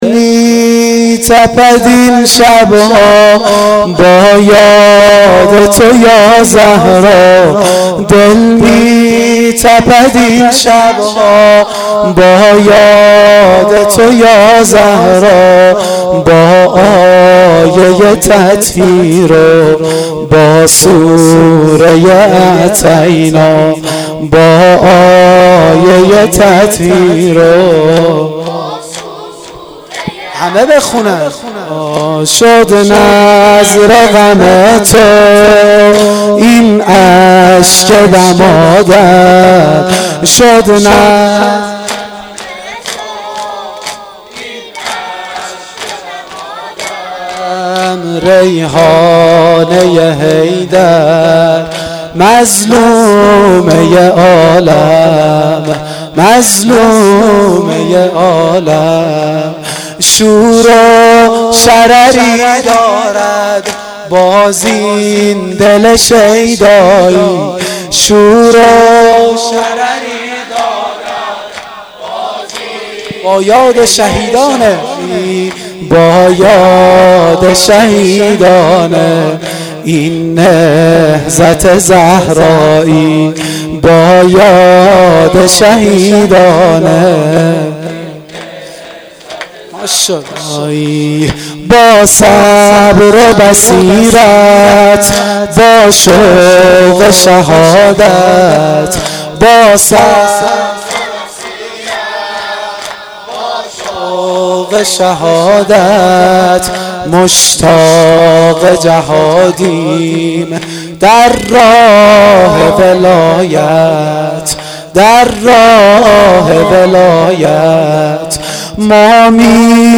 دم پایانی شب دوم فاطمیه دوم